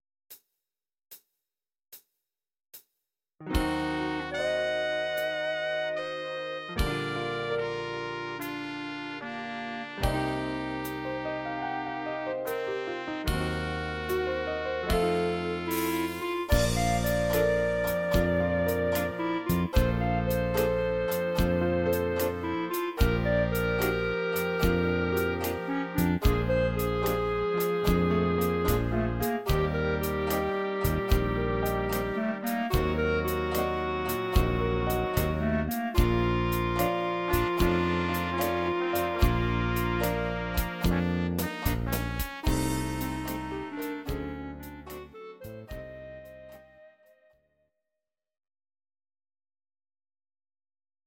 Audio Recordings based on Midi-files
Jazz/Big Band, Instrumental, 1950s